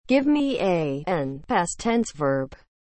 currentTTS.mp3